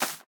Minecraft Version Minecraft Version latest Latest Release | Latest Snapshot latest / assets / minecraft / sounds / block / cave_vines / break2.ogg Compare With Compare With Latest Release | Latest Snapshot
break2.ogg